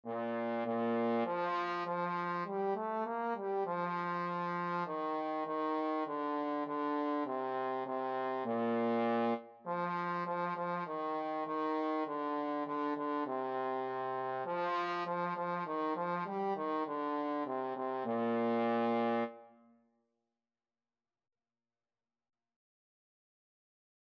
Traditional Trad. Baa, Baa Black Sheep Trombone version
4/4 (View more 4/4 Music)
Bb3-Bb4
Moderato
Bb major (Sounding Pitch) (View more Bb major Music for Trombone )
Trombone  (View more Beginners Trombone Music)
Traditional (View more Traditional Trombone Music)